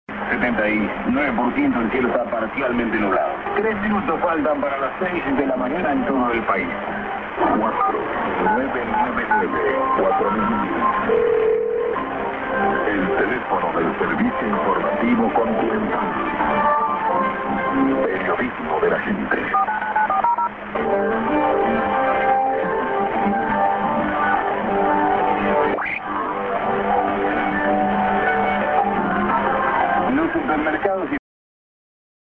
prog->pipopa->ID(man)-> アナウンスは単に"コンチネンタル"です。